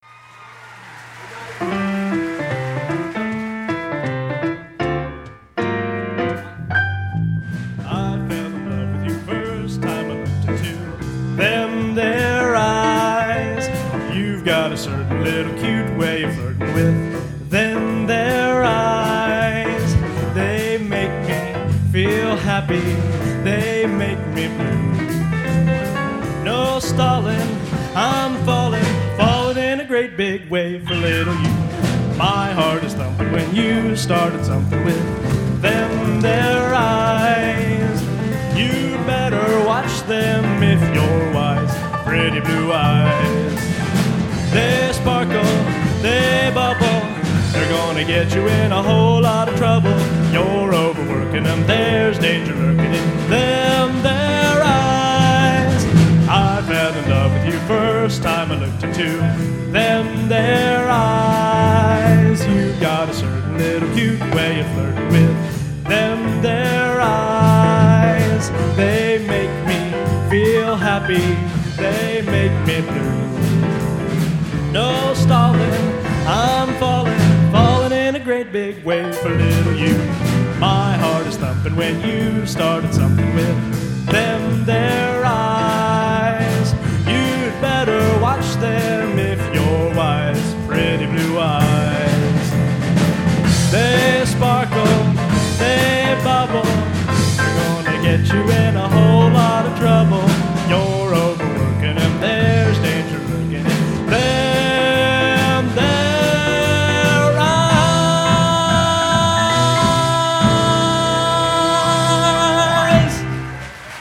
Location: Rossville High School, Rossville, Indiana
Genre: Popular / Standards | Type: Runout